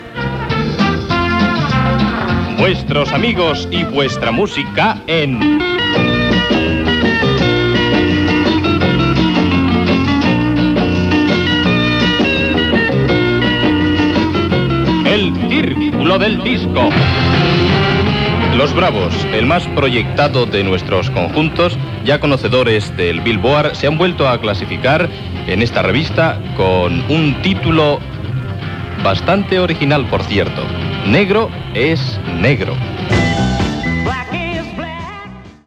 Careta del programa i presentació d'un tema de Los Bravos.
Musical